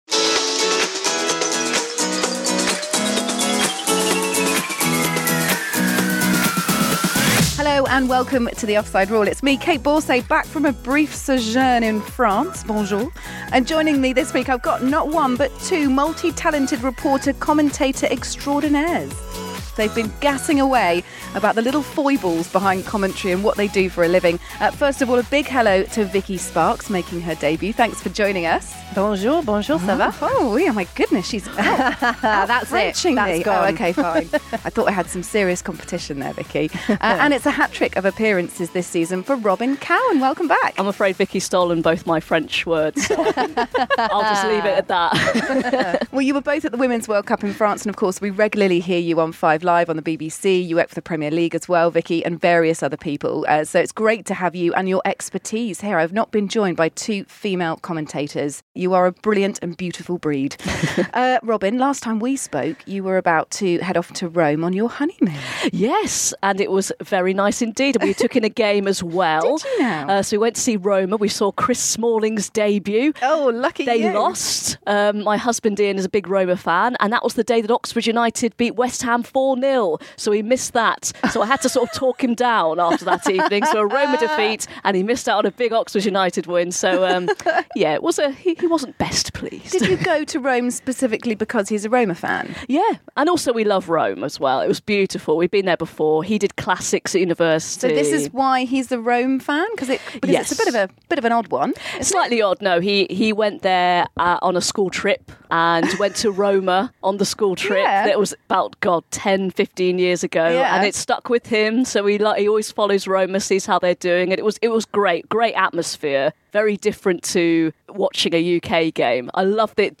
are in the studio for this week's look at the world of football.